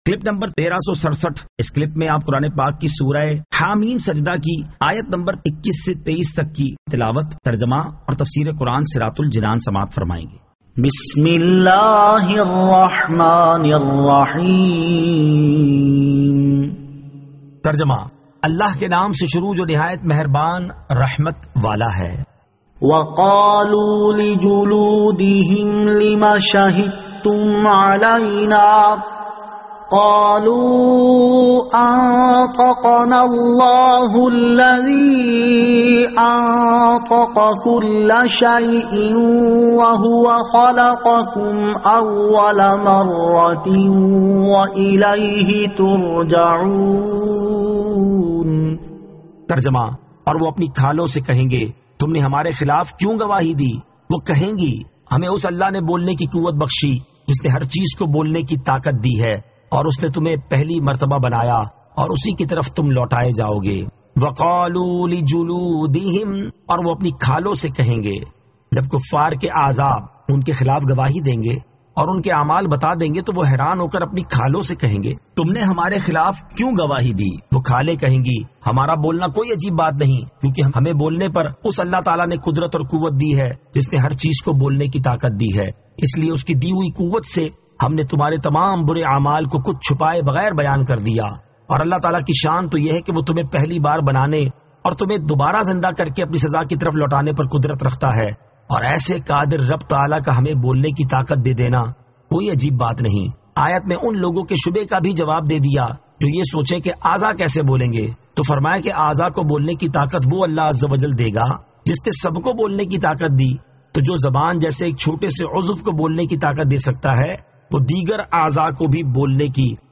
Surah Ha-Meem As-Sajdah 21 To 23 Tilawat , Tarjama , Tafseer